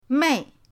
mei4.mp3